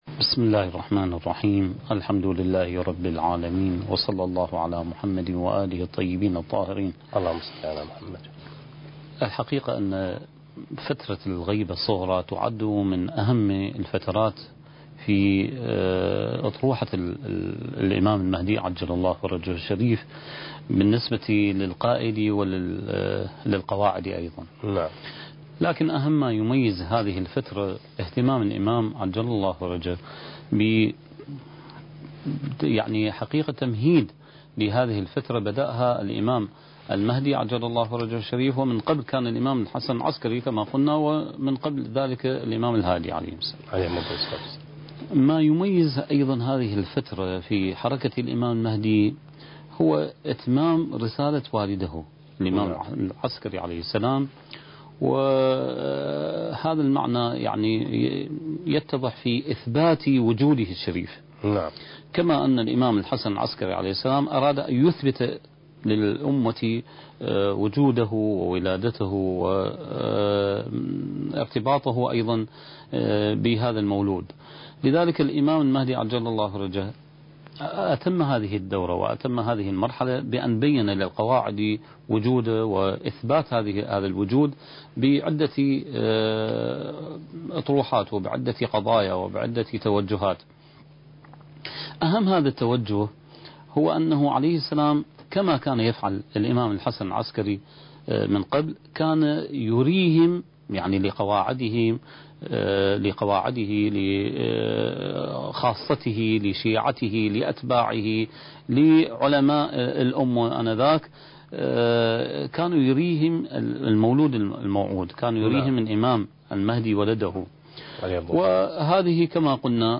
سلسلة محاضرات: بداية الغيبة الصغرى (3) برنامج المهدي وعد الله انتاج: قناة كربلاء الفضائية